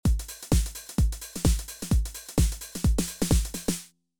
In the device’s default setting, you should now hear this two-bar pattern when you hit play. It’s variation A (one-bar) followed by B (one-bar):